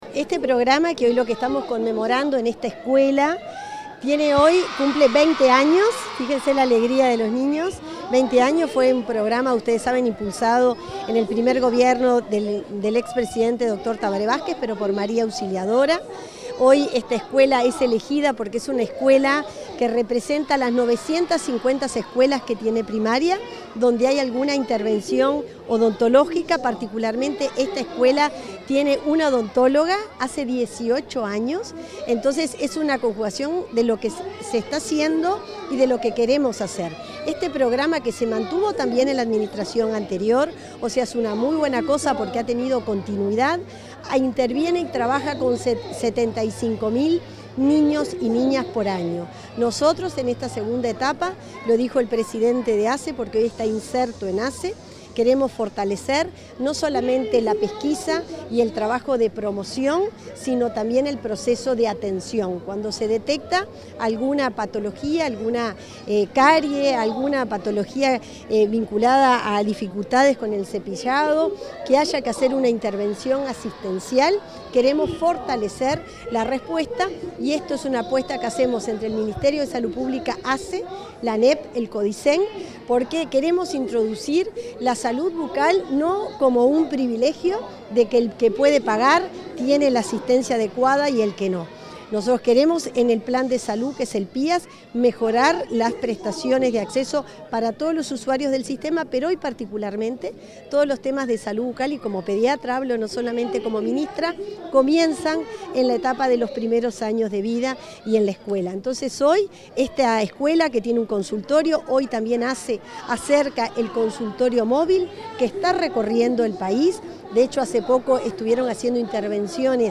Declaraciones de la ministra de Salud Pública, Cristina Lustemberg
La ministra de Salud Pública, Cristina Lustemberg, fue entrevistada por medios de prensa tras la celebración del 20.° aniversario del Programa